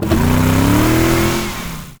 car-engine-load-4.ogg